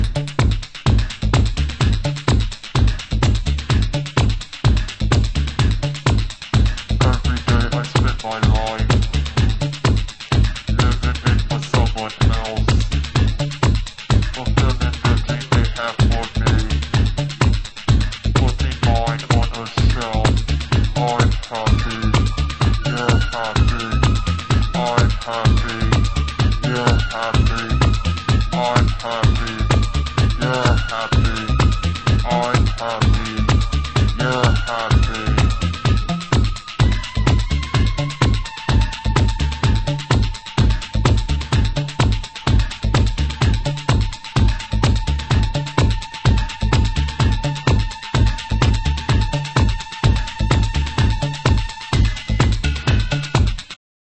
○シカゴハウス/テクノをベースにユニークなサウンドメイクのエレクトロ・ファンク！